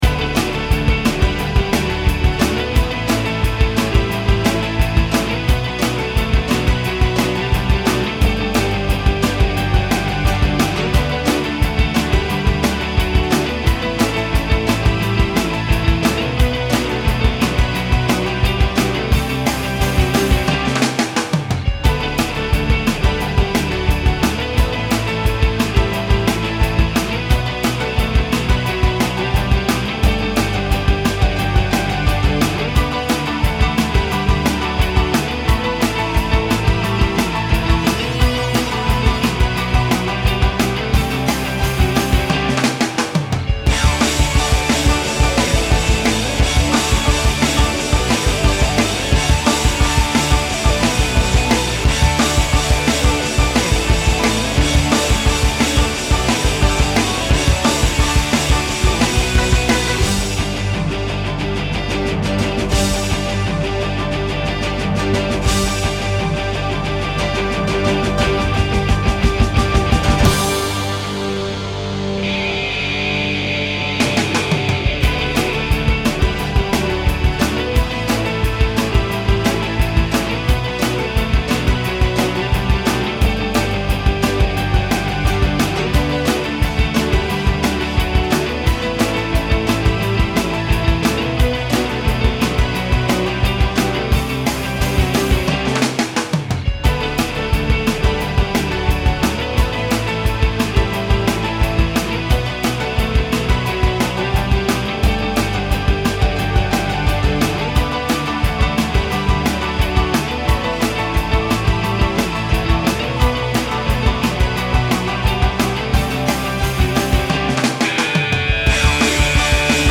pop
rock
Sonidos: Música